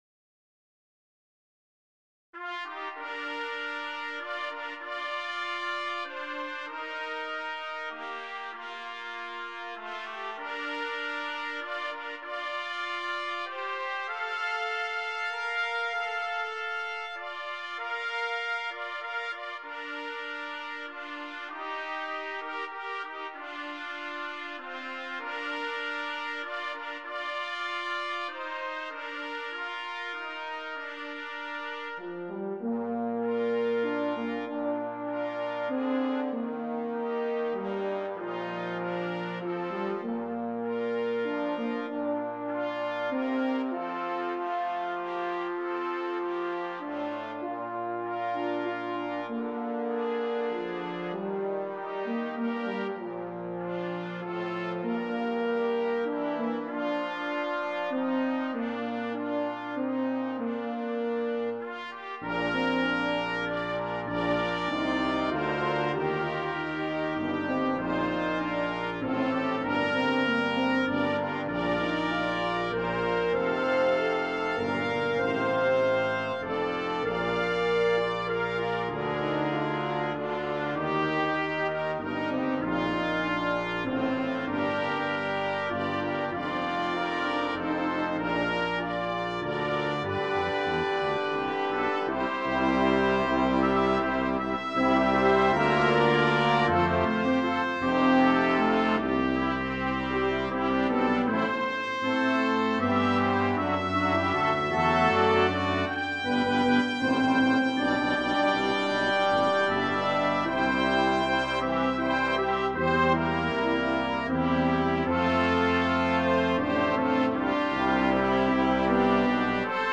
Voicing: Brass Sextet